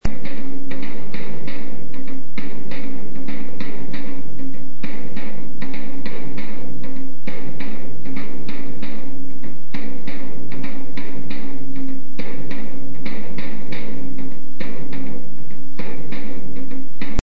《太鼓》
闇を破き，天地を揺さぶる太鼓の音。子供のたたく小太鼓から数人でたたく大型太鼓まで太鼓の乗りに祭りは絶頂に達します。